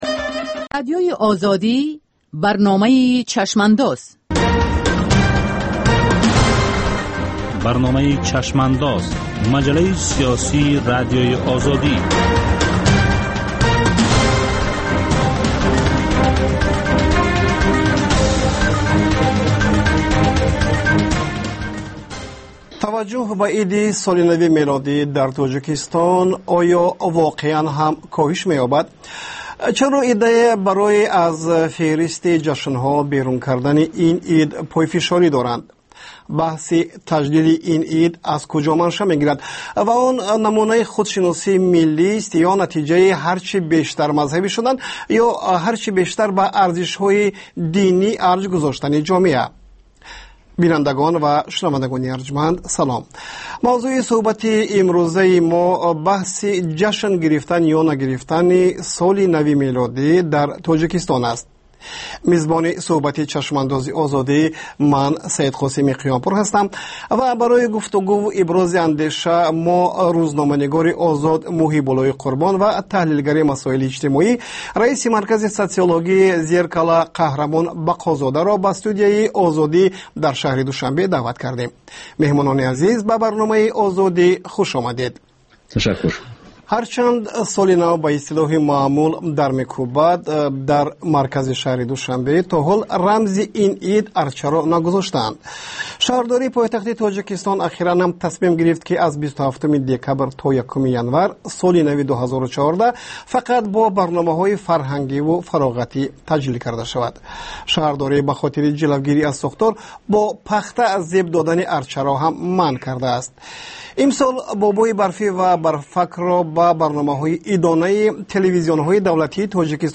Нигоҳе ба таҳаввулоти сиёсии Тоҷикистон, минтақа ва ҷаҳон дар ҳафтае, ки гузашт. Гуфтугӯ бо сиёсатмадорон ва коршиносон.